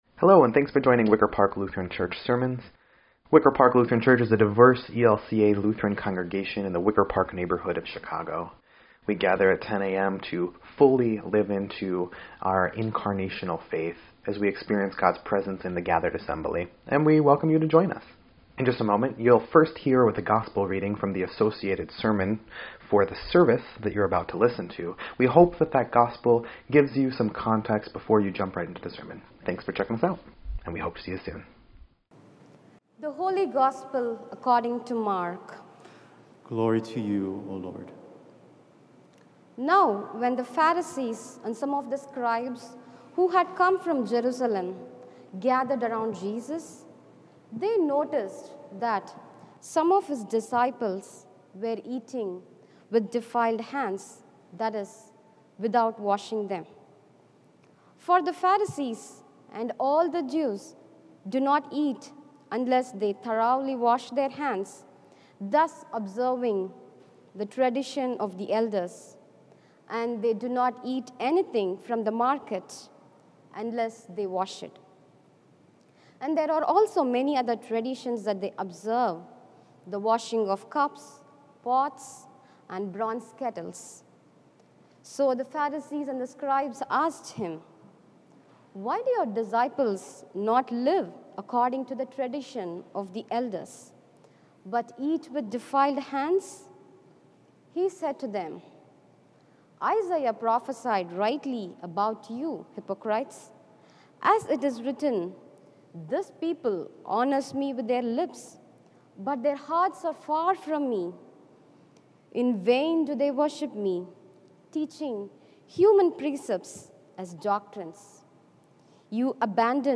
8.29.21-Sermon_EDIT.mp3